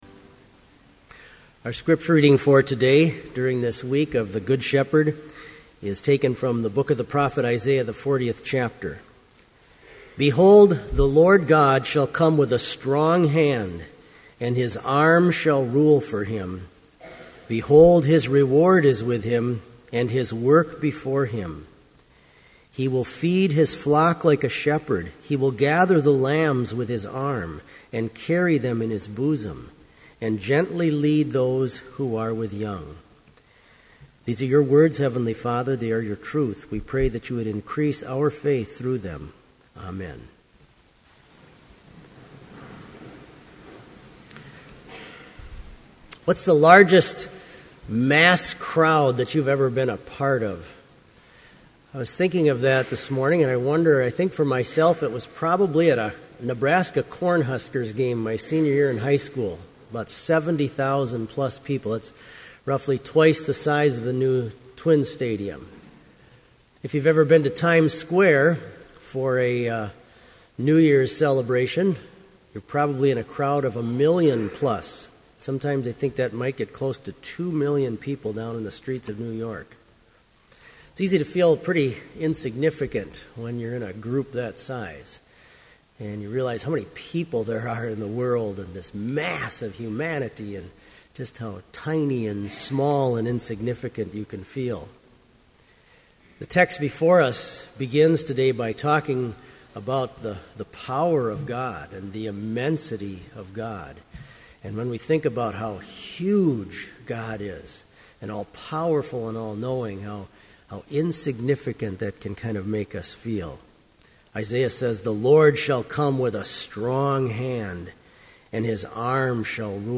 Complete service audio for Chapel - April 23, 2012